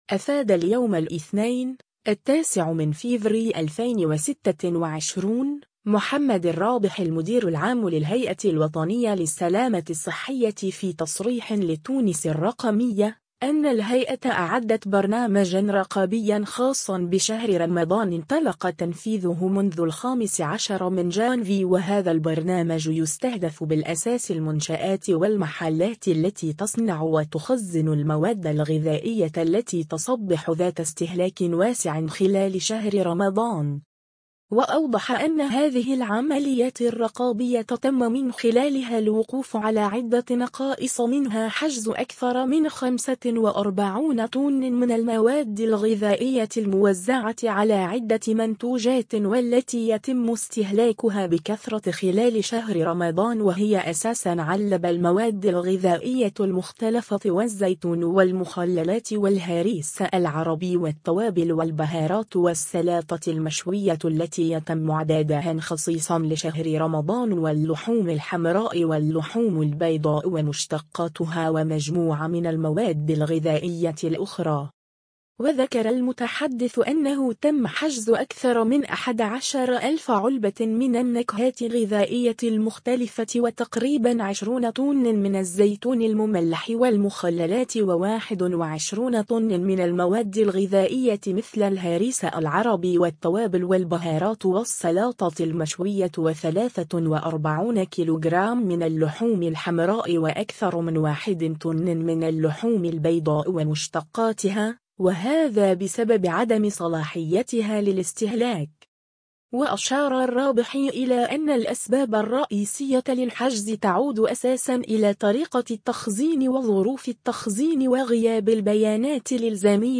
أفاد اليوم الإثنين، 09 فيفري 2026، محمد الرّابحي المدير العام للهيئة الوطنيّة للسلامة الصّحية في تصريح لتونس الرّقمية، أنّ الهيئة أعدّت برنامجا رقابيا خاصا بشهر رمضان انطلق تنفيذه منذ 15 جانفي و هذا البرنامج يستهدف بالأساس المنشآت و المحلات التي تصنّع و تخزّن المواد الغذائيّة التي تصبح ذات استهلاك واسع خلال شهر رمضان.